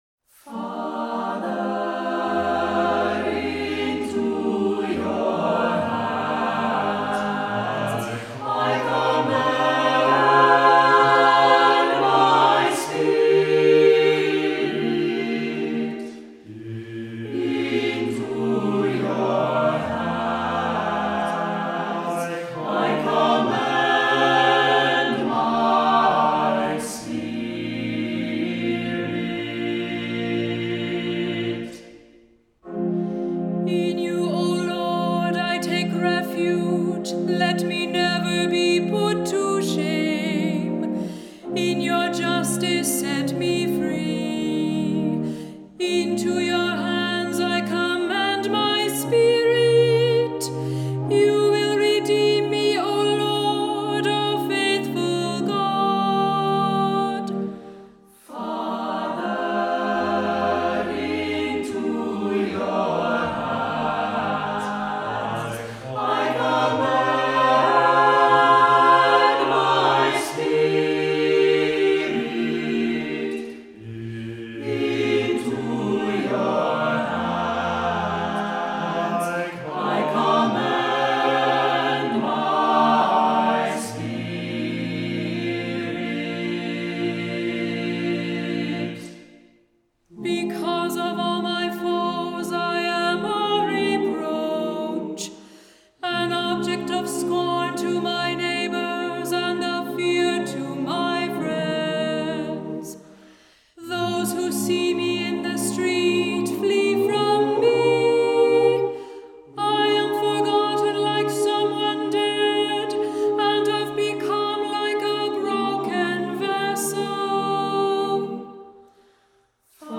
Voicing: SATB a cappella; Cantor; Assembly